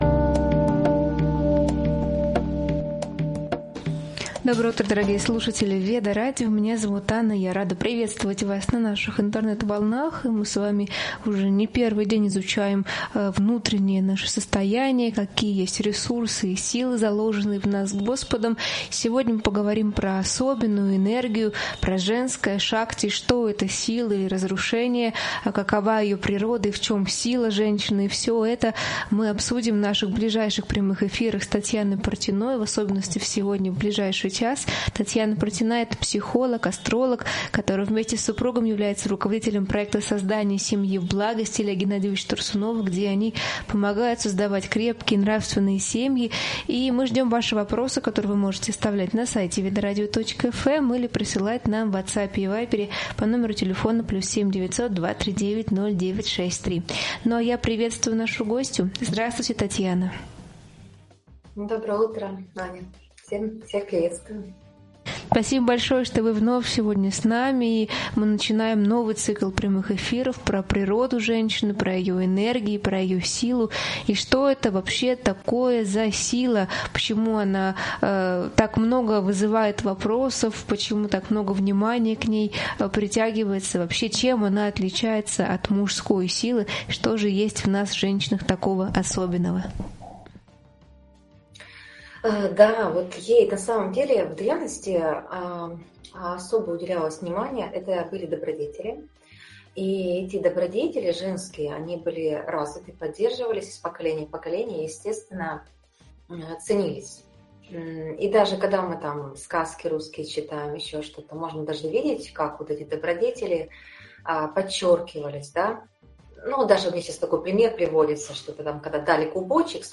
В этом эфире — разговор о кармических сценариях в отношениях, природе женской энергии и глубине молитвы.